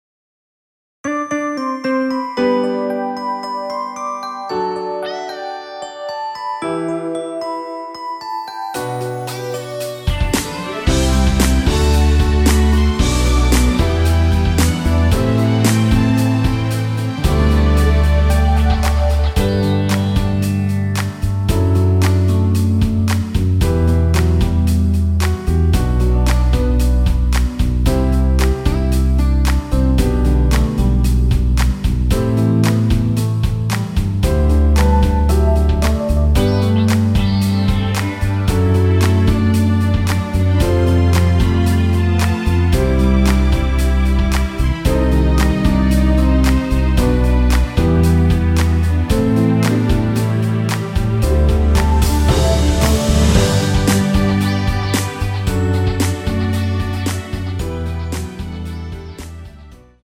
한키 (-2)내린 MR입니다.
앞부분30초, 뒷부분30초씩 편집해서 올려 드리고 있습니다.
중간에 음이 끈어지고 다시 나오는 이유는